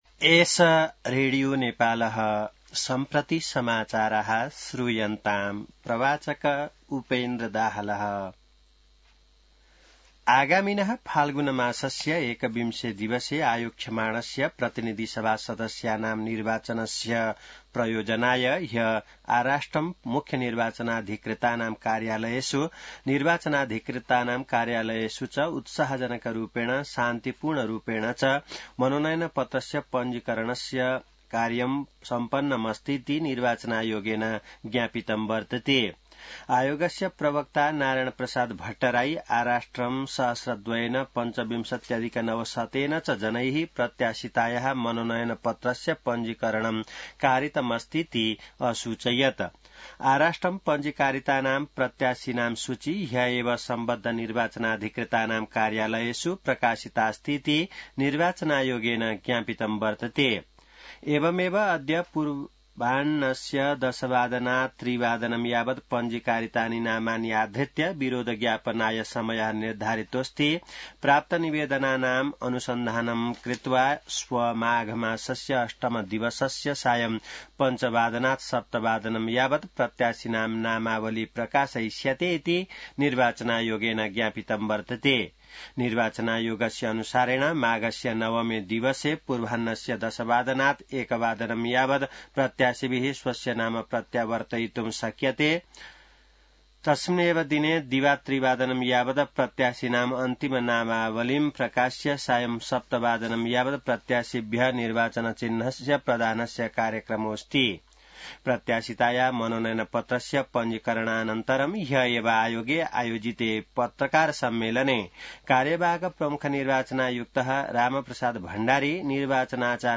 संस्कृत समाचार : ७ माघ , २०८२